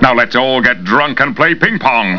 That was the incoming mail sound on my computer for many months.
pingpong.wav